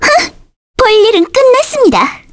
Aisha-Vox_Skill3_kr.wav